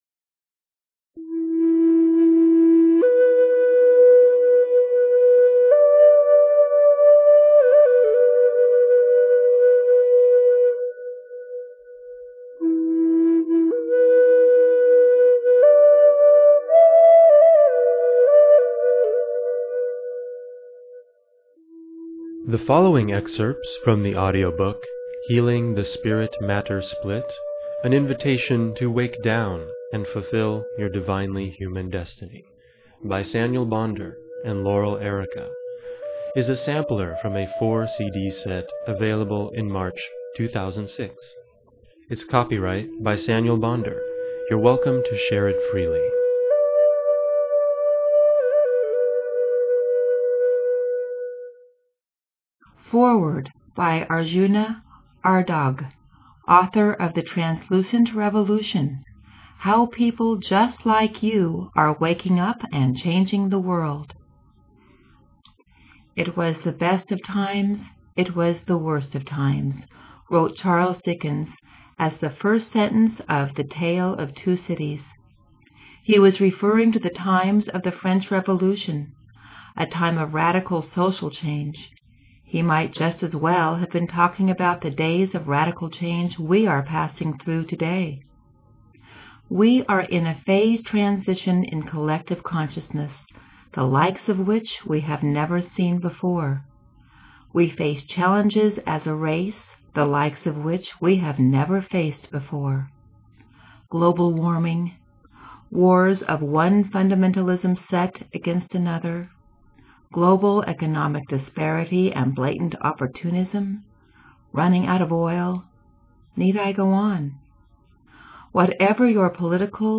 Healing the Spirit/Matter Split Audio Sampler